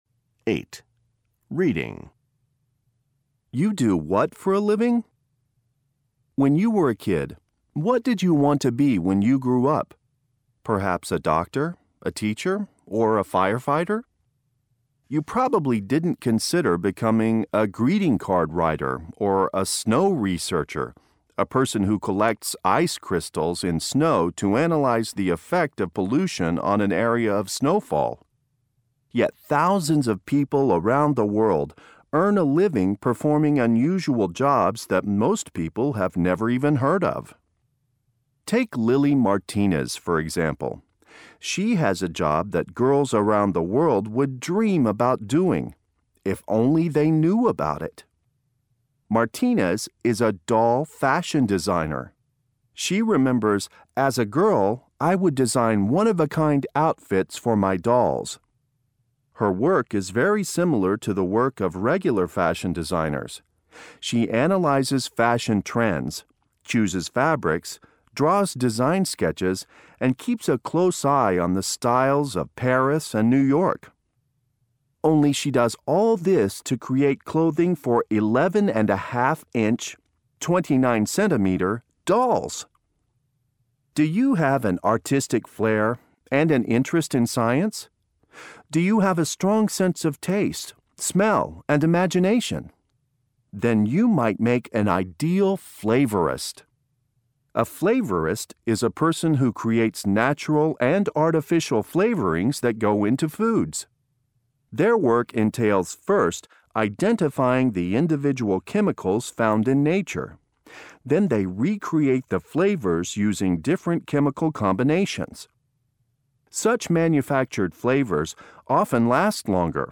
مقطع للوحده الخامسه MG22_U5_L8_Reading_Track